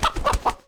CosmicRageSounds / wav / general / combat / creatures / CHİCKEN / he / hurt2.wav
hurt2.wav